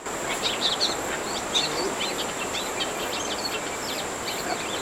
コムクドリの”さえずり”
入り口脇のムクノキの茂みから複雑な鳥の”さえずり”が聞こえて来た。
春の渡りでもキュルキュルに混じって”さえずり”が聞けることがある。
本日の”さえずり”録音はココ